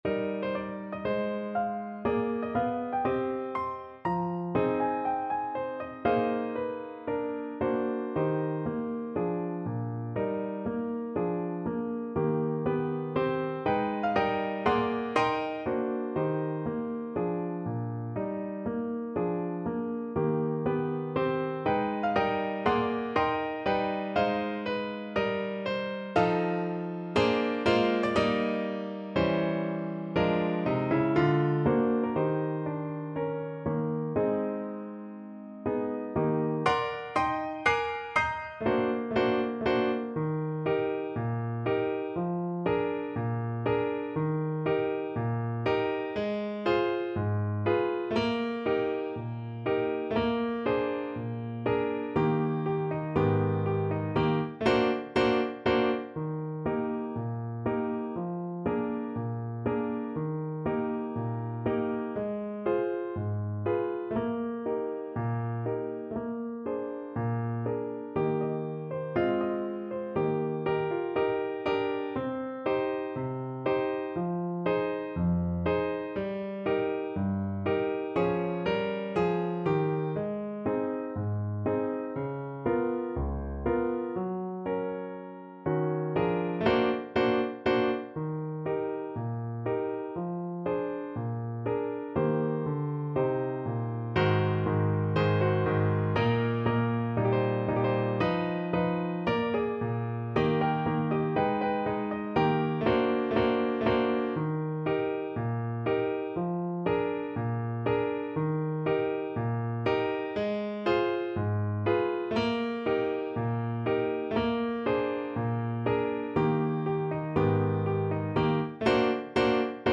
~ = 120 Moderato